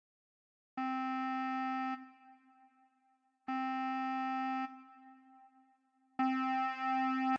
Six_Cents_Interval.ogg